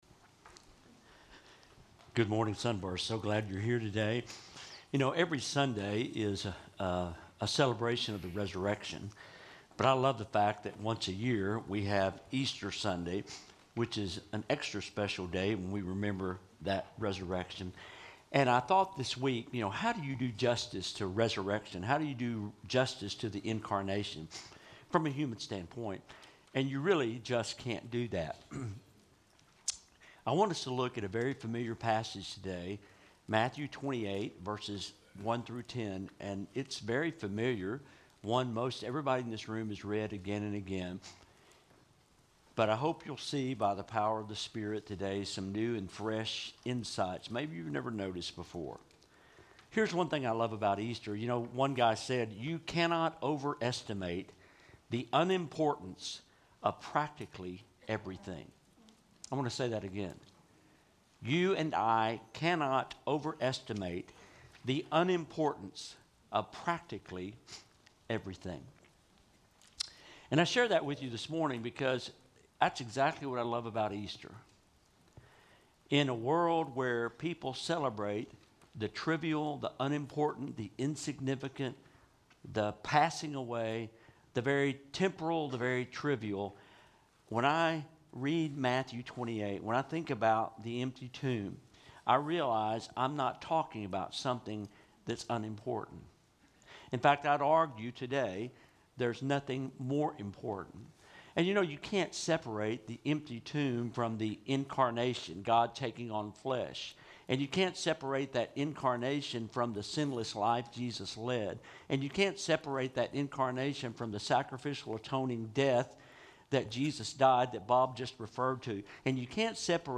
Lesson